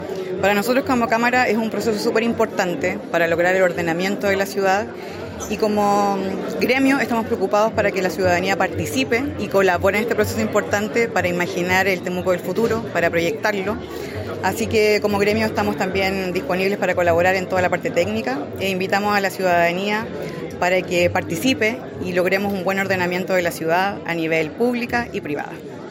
La actividad, realizada en la sala de Concejo de la Municipalidad de Temuco, reunió a autoridades comunales, regionales, ancestrales, dirigentes y dirigentas sociales, además de representantes del mundo público y privado.